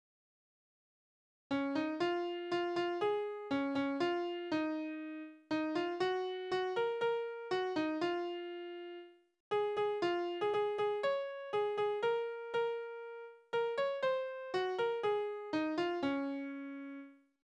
Naturlieder
Tonart: Des-Dur
Taktart: 6/8
Tonumfang: Oktave
Anmerkung: - Vortragsbezeichnung: langsam - weitere Teile der Vortragsbezeichnung sind unleserlich